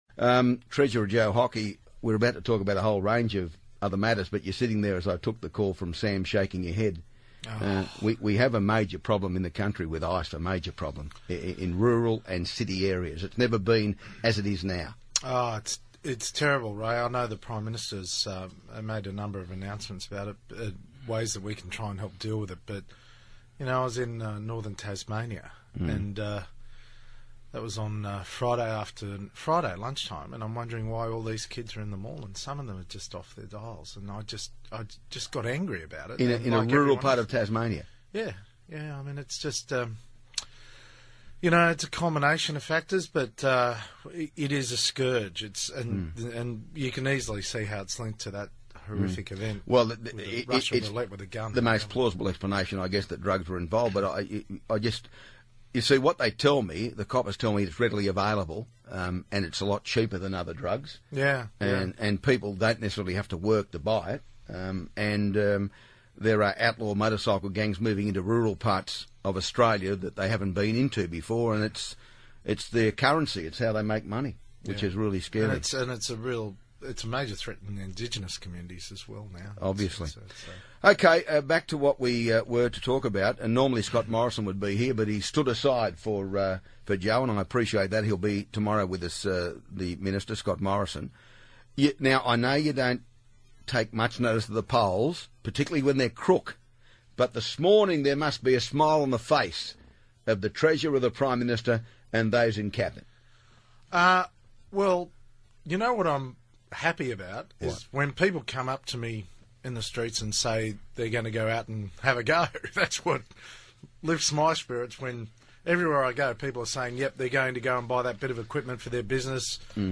Treasurer Joe Hockey joins Ray in the studio to talk about the polls going up, Ministers not declaring double dipping, and Leigh Sales’ post-budget interviews